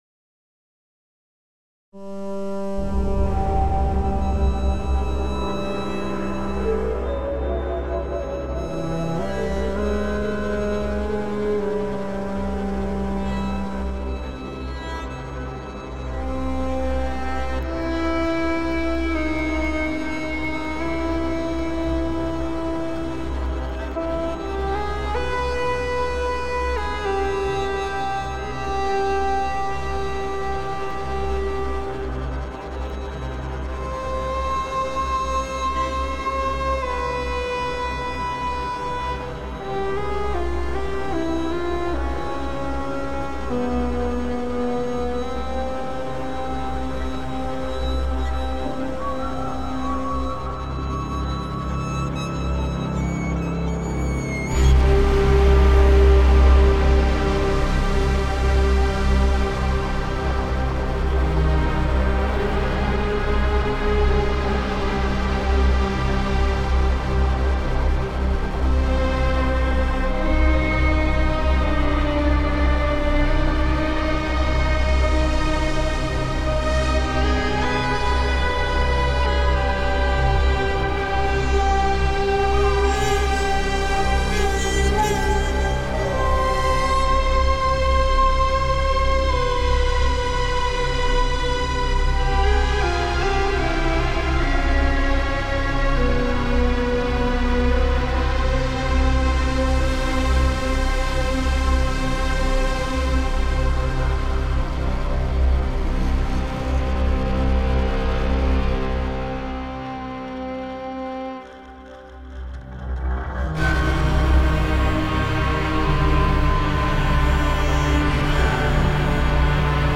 an epic blend of orchestral and electronic music